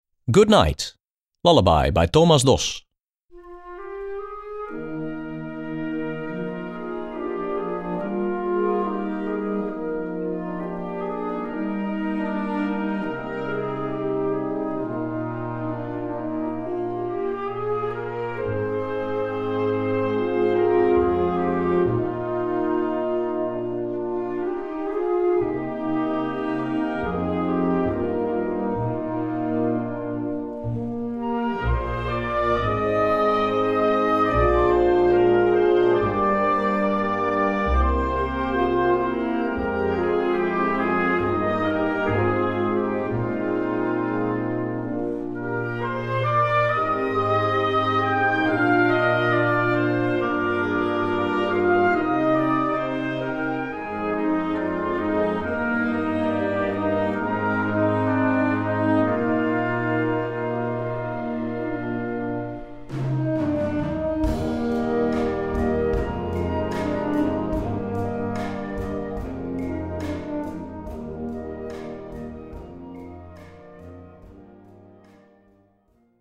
Gattung: Lullaby
Besetzung: Blasorchester